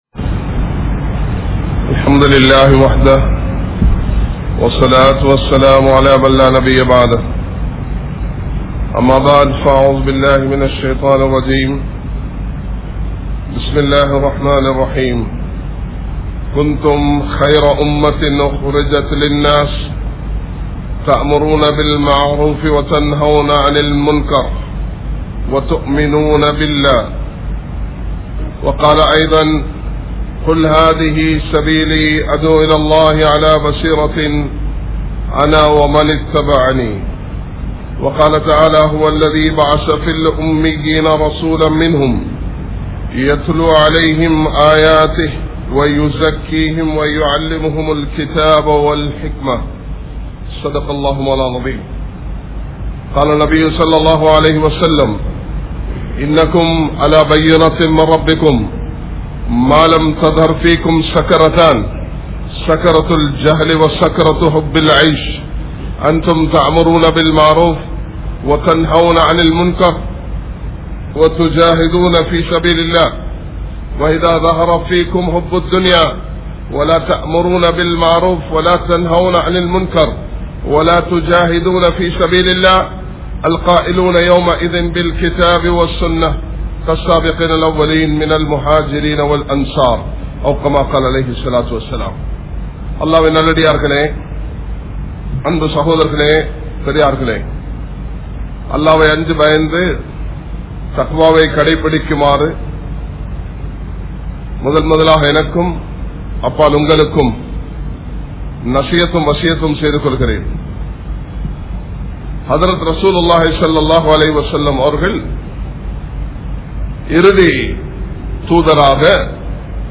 Aniyaayam Seithavarhalin Mudivuhal | Audio Bayans | All Ceylon Muslim Youth Community | Addalaichenai
Kollupitty Jumua Masjith